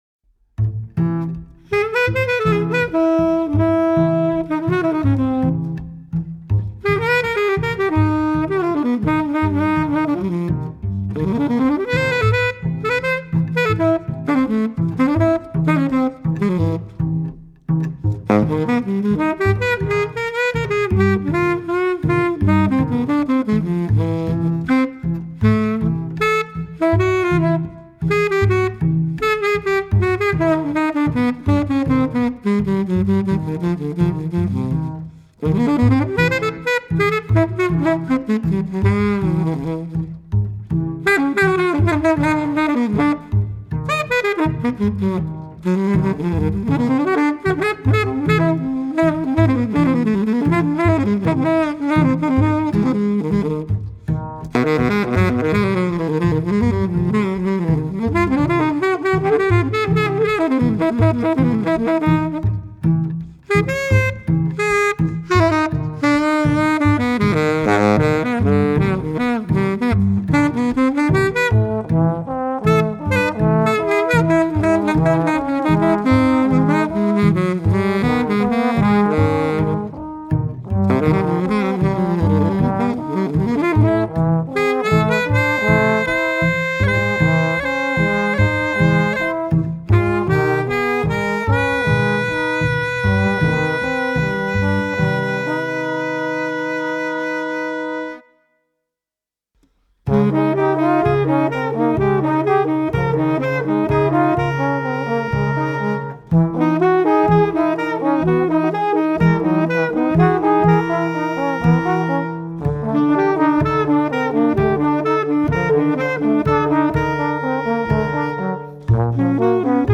saxophonist
bassist
trombonist
heartfelt, poetic and organic.